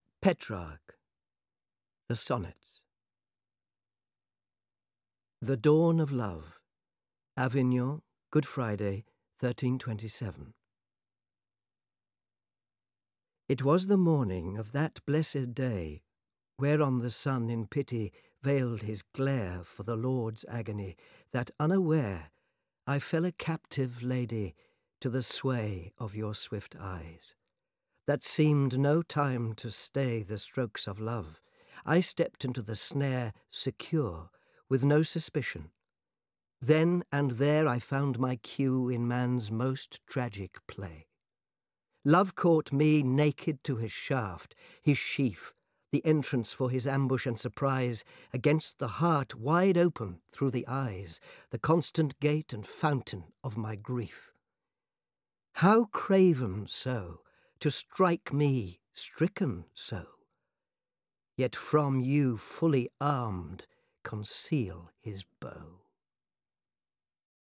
Ukázka z knihy
• InterpretAnton Lesser